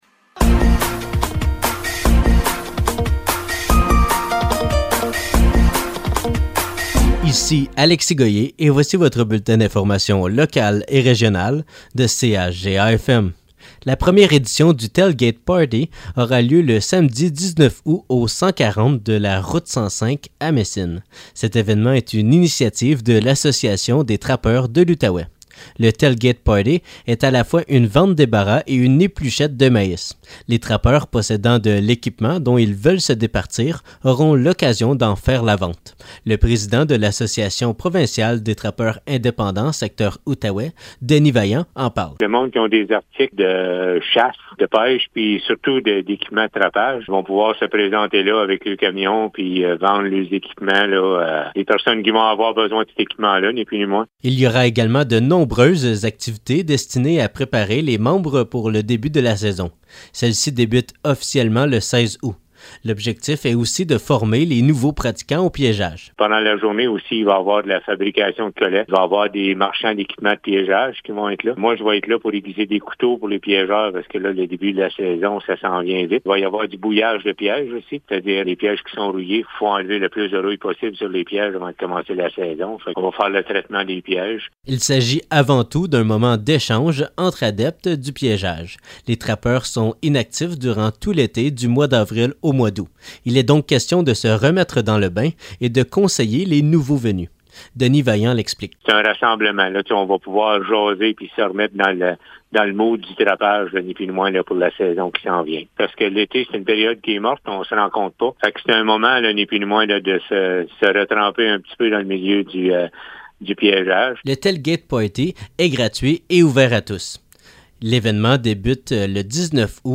Nouvelles locales - 9 août 2023 - 12 h